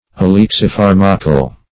Search Result for " alexipharmacal" : The Collaborative International Dictionary of English v.0.48: Alexipharmac \A*lex`i*phar"mac\, Alexipharmacal \A*lex`i*phar"ma*cal\, a. & n. [See Alexipharmic .]